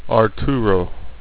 A good example is the word Arturo, which begins and ends with a vowel sound, though our program returns a consonant at beginning and end. This is because of the dead space that is inherent at the start and end of file, due to the delay between recording beginning and the speech sample starting (and similarly at the end).